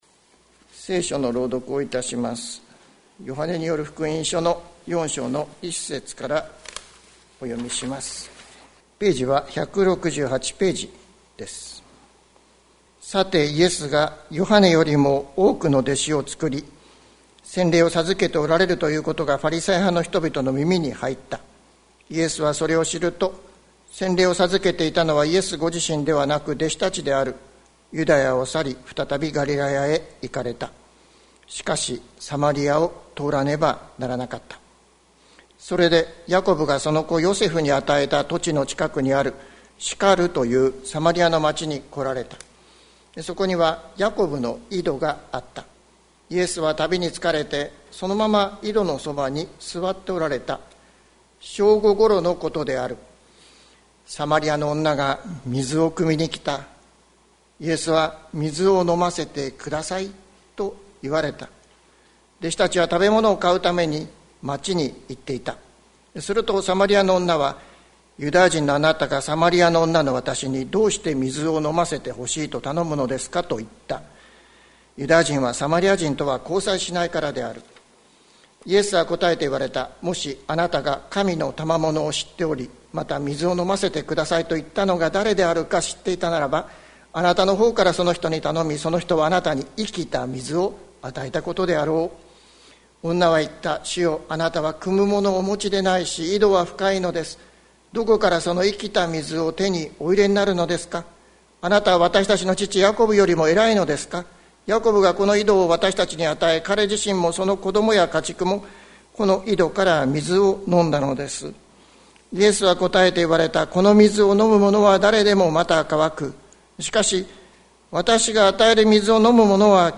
2022年01月30日朝の礼拝「いのちはここにある」関キリスト教会
説教アーカイブ。